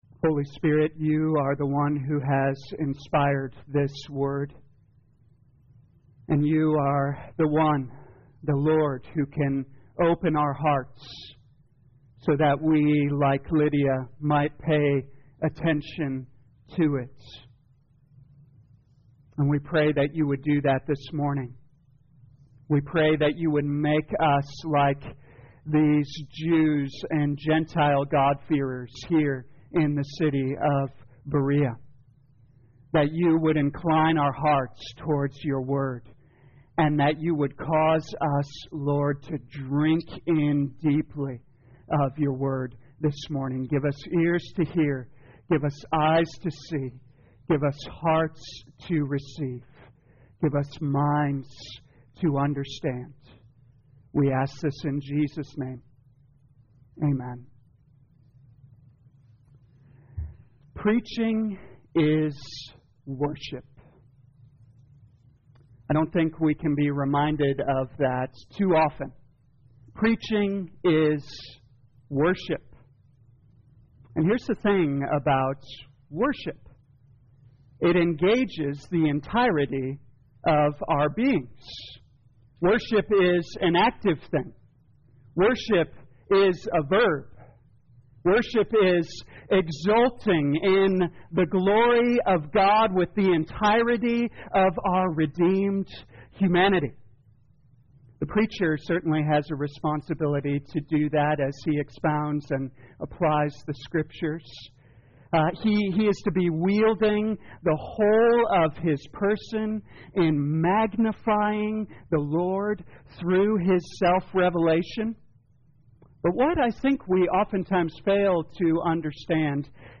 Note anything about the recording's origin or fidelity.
2022 Acts Morning Service Download